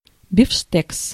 Ääntäminen
IPA: [bif.tɛk]